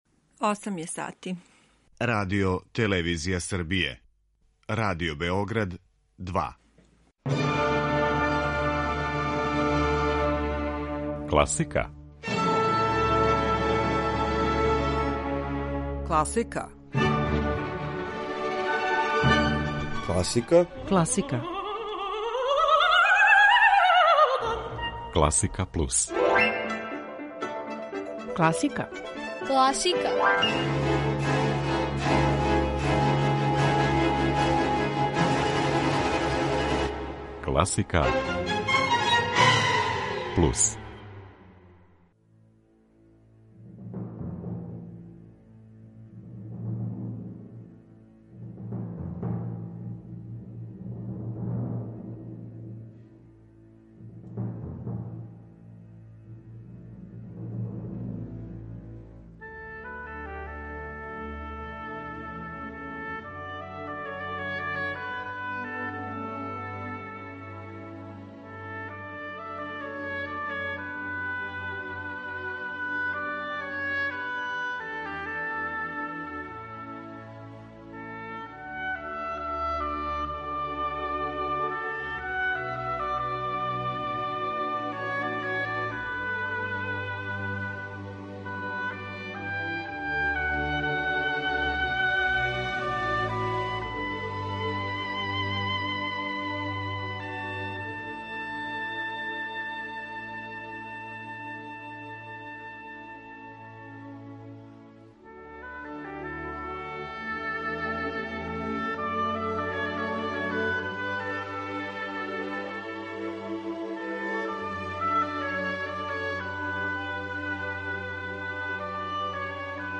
Музика природе, цвркут птица, Монтеверди и Бах, али и извођачи међу којима су ансамбл L'Arpeggiata и Кристиjан Плухар, Жорди Саваљ, Натали Штуцман и Владимир Хоровиц, део су поетичног избора наше истакнуте уметнице.